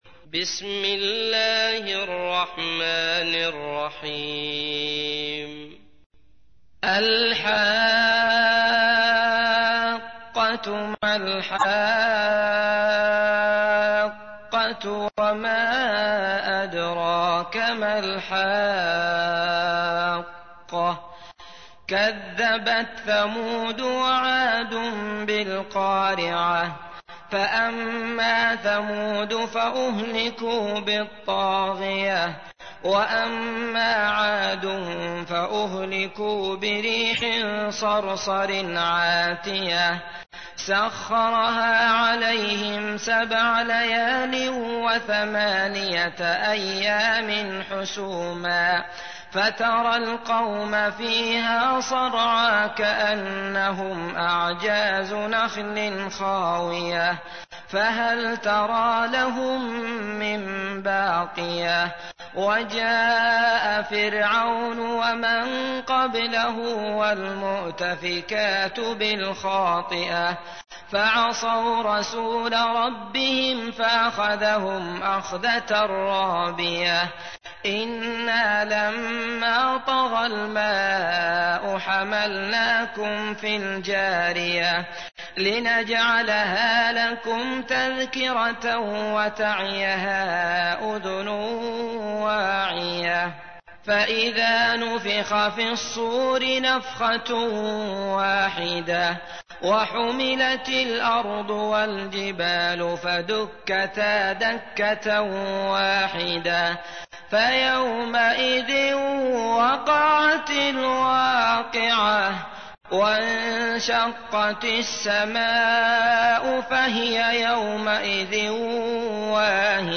تحميل : 69. سورة الحاقة / القارئ عبد الله المطرود / القرآن الكريم / موقع يا حسين